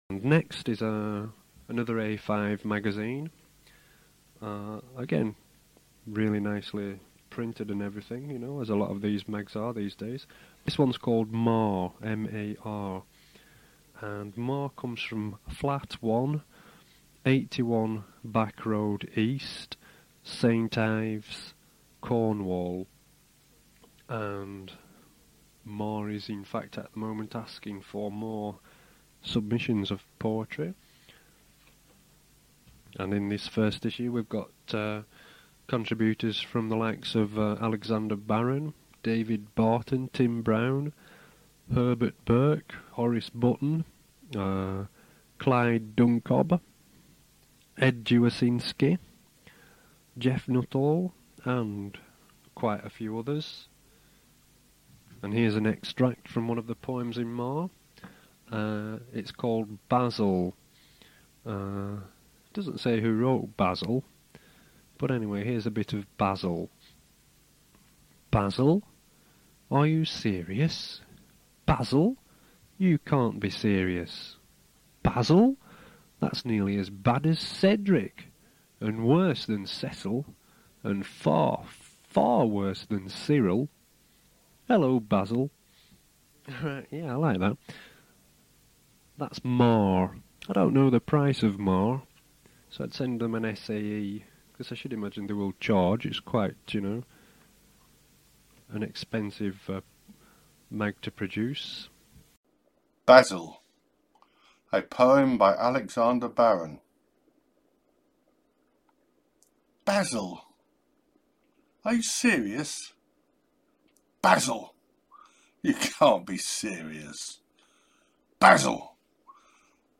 a poem
recited by me